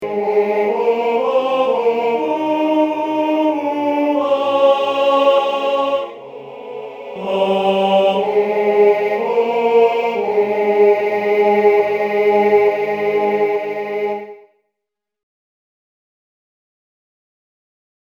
Key written in: A♭ Major
How many parts: 4